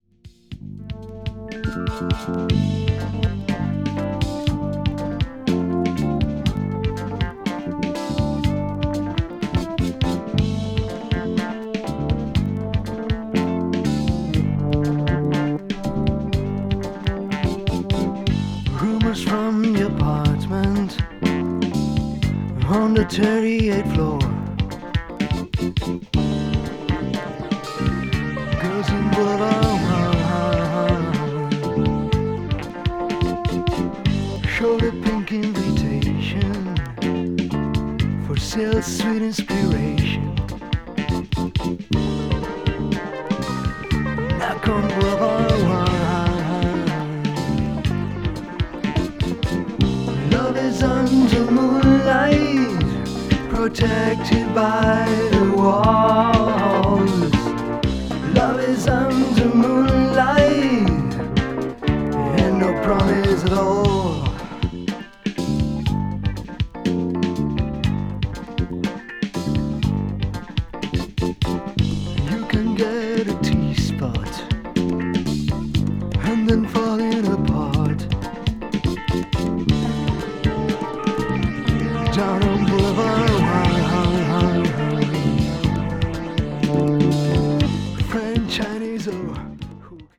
a.o.r.   blues rock   country rock   folk rock